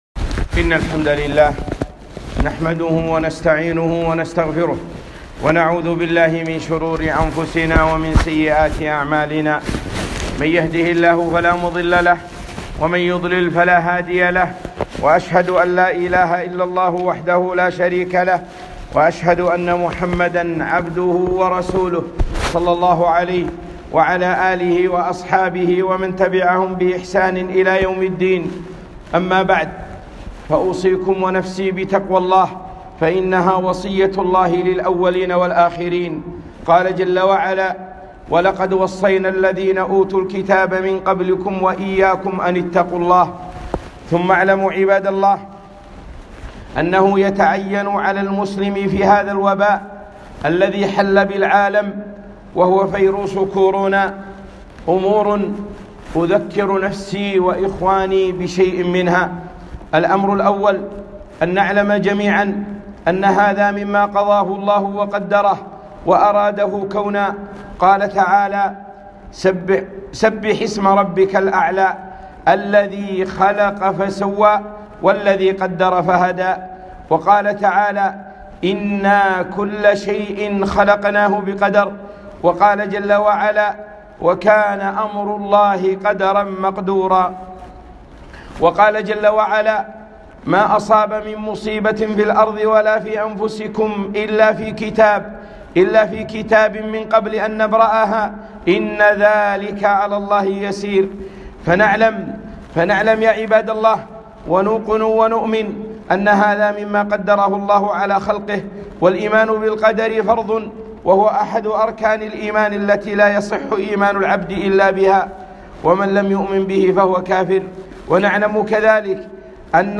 خطبة - الأمور التي يفعلها المسلم مع كورونا